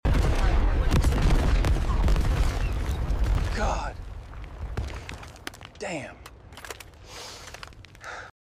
Blasters flying. Chaos everywhere.